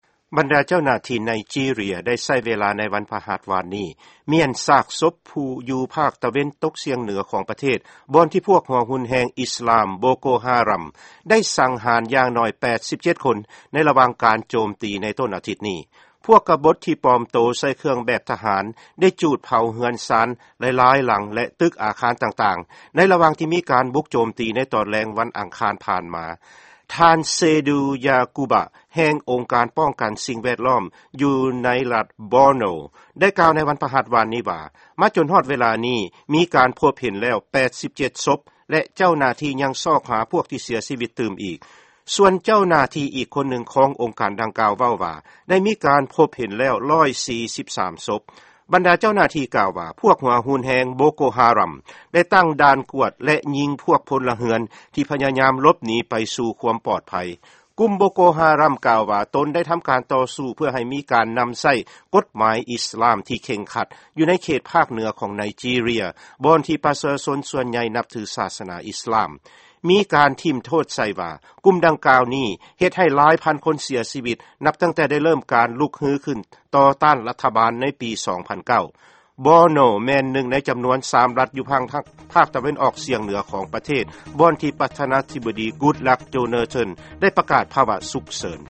ຟັງຂ່າວ ປະເທດໄນຈີເຣຍ